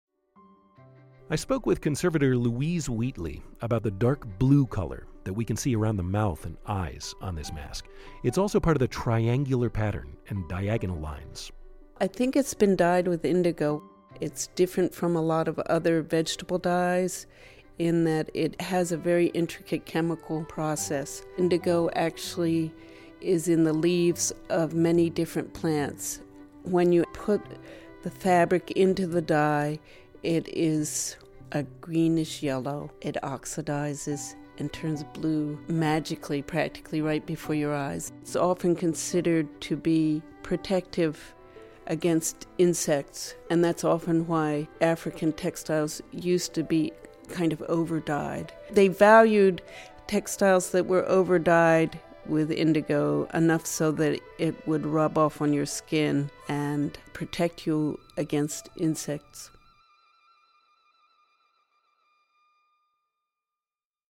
A Conversation About Materials